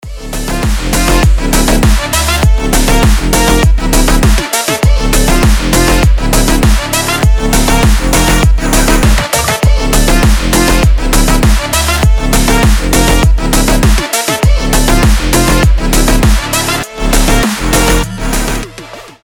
• Качество: 320, Stereo
EDM
мощные басы
Bass House
качающие
взрывные